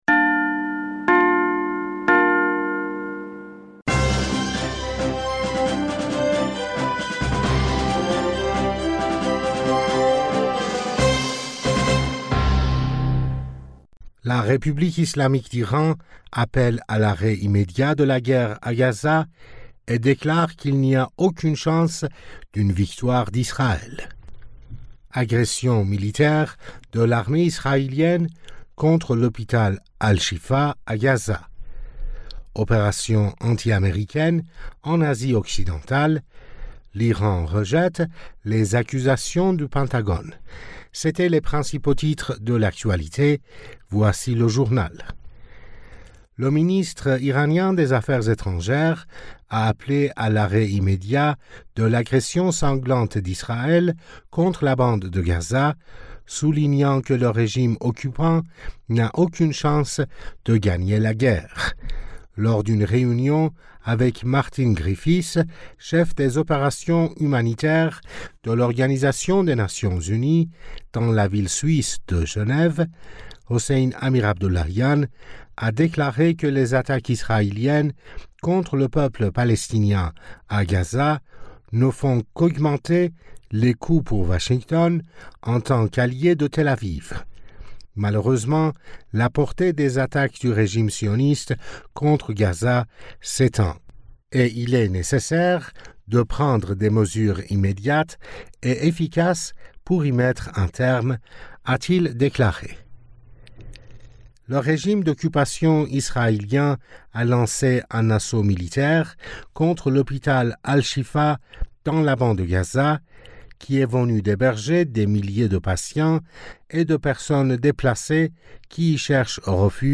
Bulletin d'information du 14 Novembre 2023